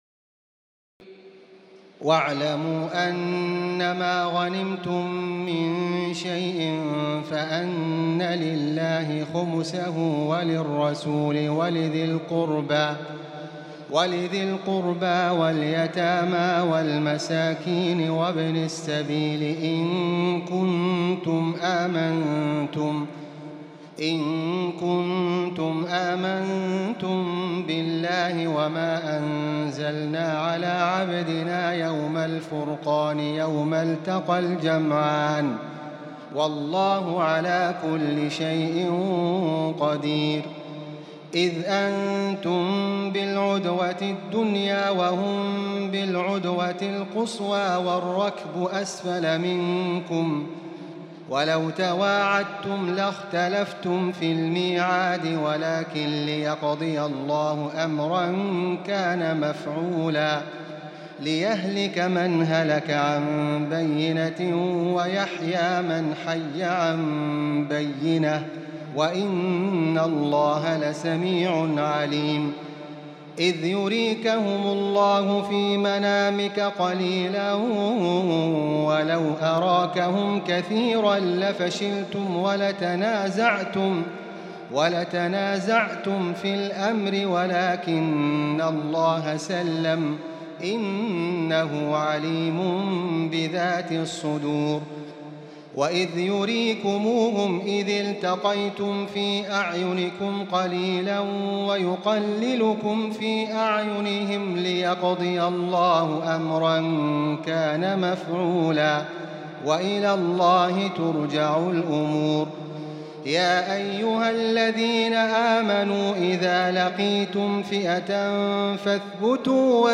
تراويح الليلة التاسعة رمضان 1438هـ من سورتي الأنفال (41-75) و التوبة (1-33) Taraweeh 9 st night Ramadan 1438H from Surah Al-Anfal and At-Tawba > تراويح الحرم المكي عام 1438 🕋 > التراويح - تلاوات الحرمين